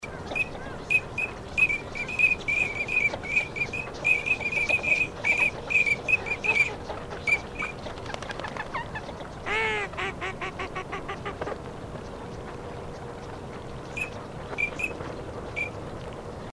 دانلود آهنگ خوتکا از افکت صوتی انسان و موجودات زنده
دانلود صدای خوتکا از ساعد نیوز با لینک مستقیم و کیفیت بالا
جلوه های صوتی